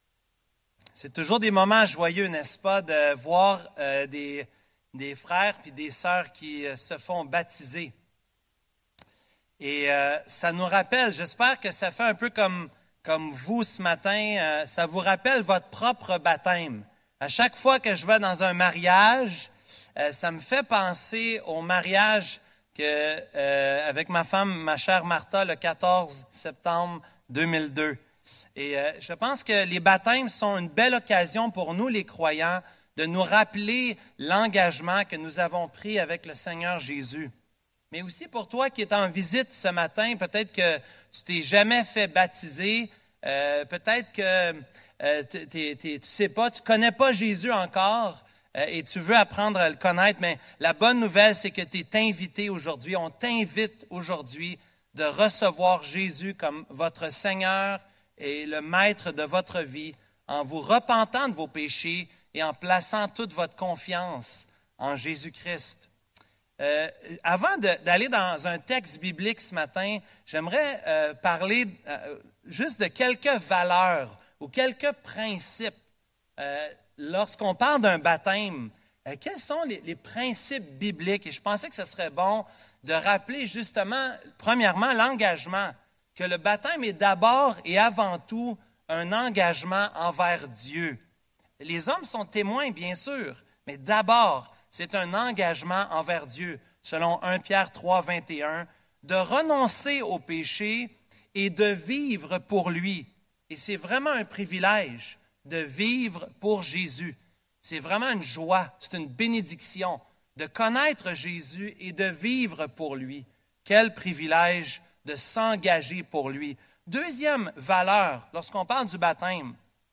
Église Baptiste Évangélique Emmanuel - Culte du 16 Juin 2024 - Romains 6.1-4